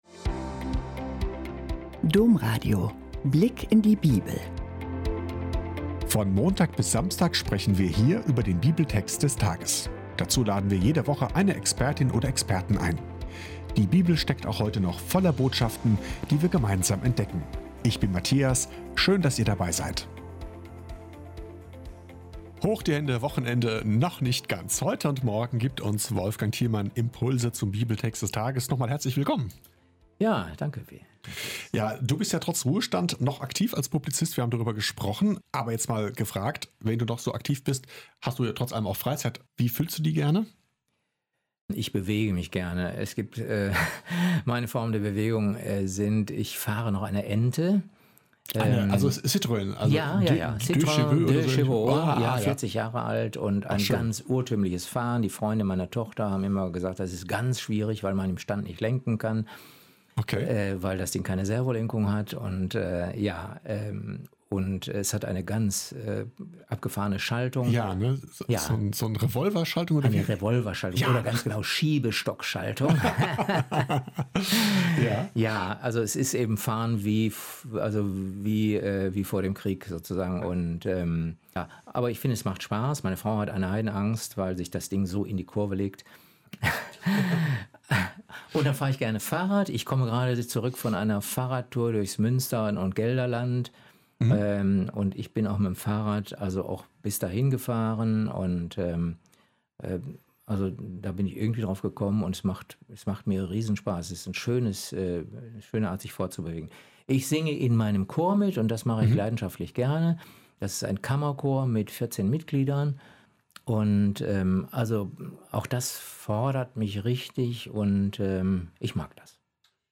Kannibalismus in der Bibel? – Gespräch